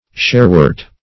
Search Result for " sharewort" : The Collaborative International Dictionary of English v.0.48: Sharewort \Share"wort`\ (sh[^a]r"w[^u]rt`), n. (Bot.)